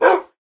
bark3.ogg